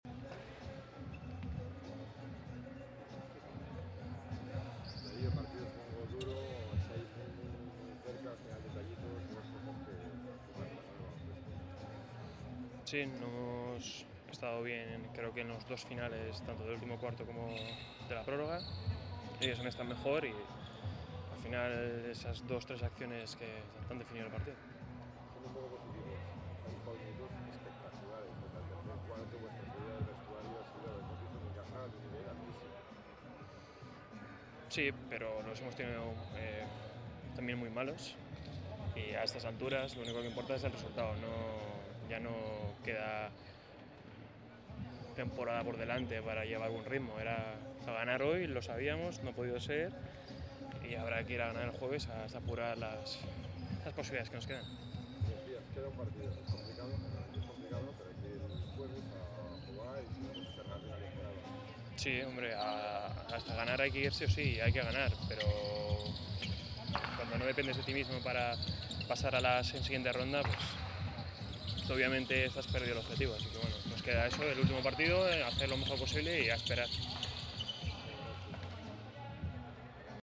Darío Brizuela habla en zona mixta de la derrota del Unicaja ante el Baskonia